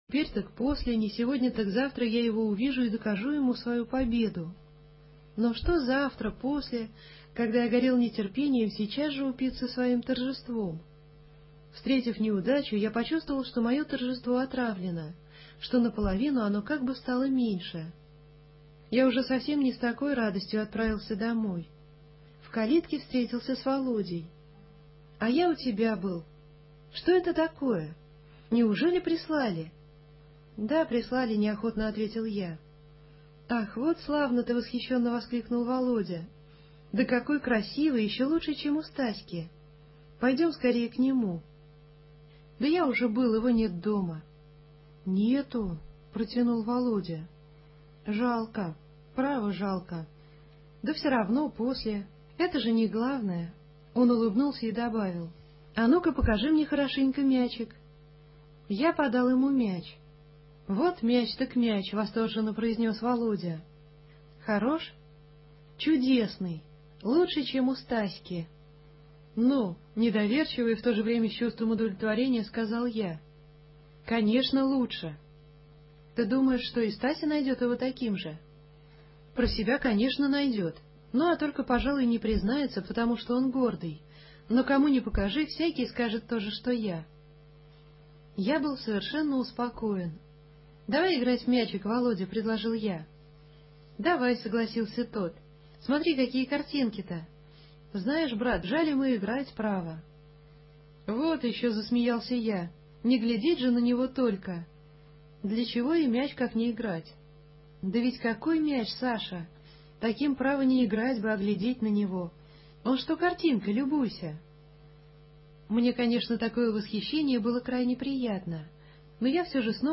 Лекция о спасении проф. МДА А.И. Осипова